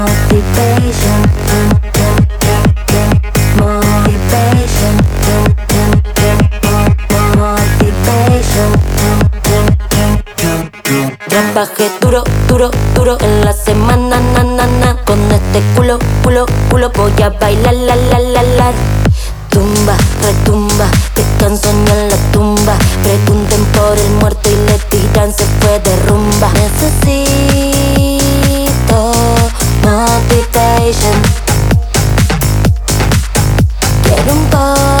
Жанр: Поп / Русские
# Pop in Spanish